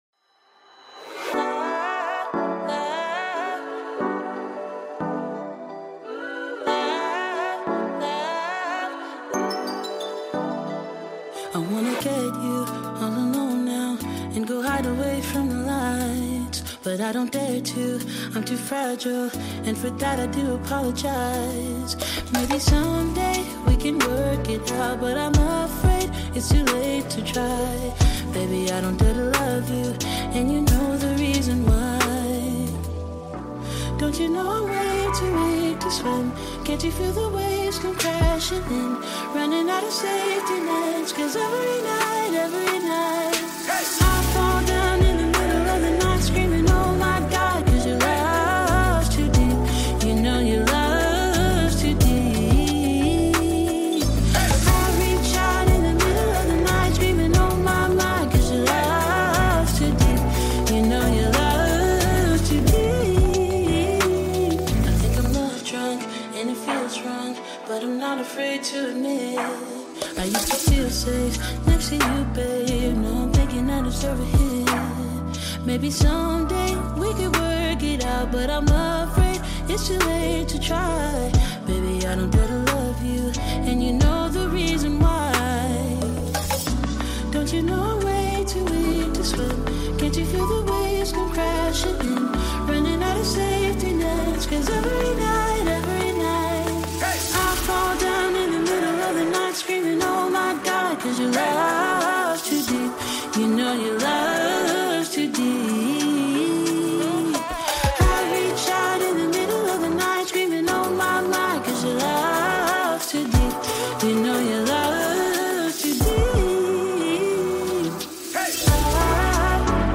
Pomodoro 2h : Clarté 528 Hz